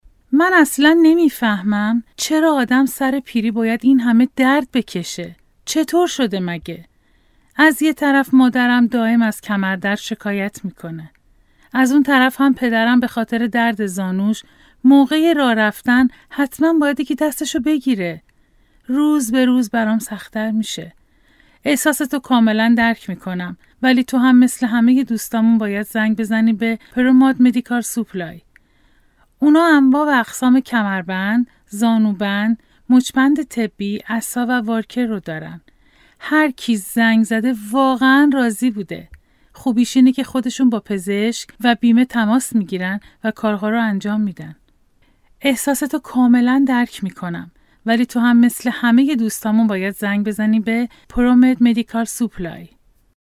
Female
Commercail